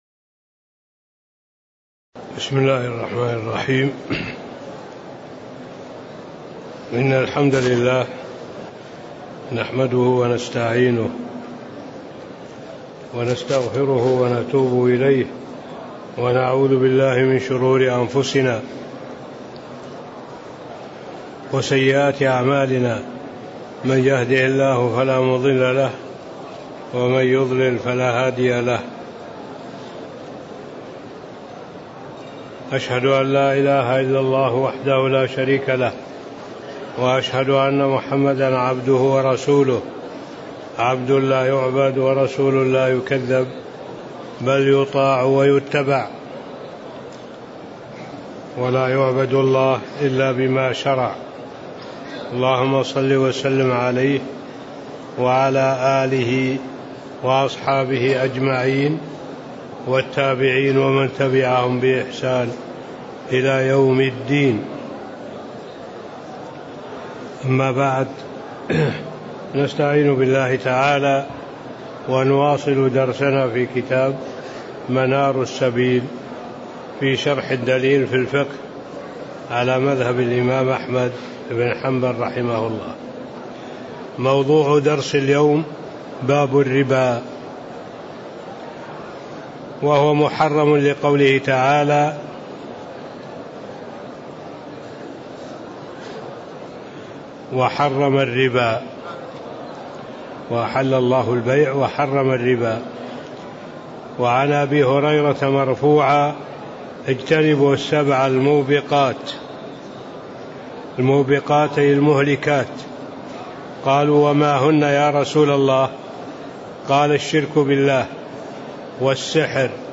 تاريخ النشر ٦ محرم ١٤٣٧ هـ المكان: المسجد النبوي الشيخ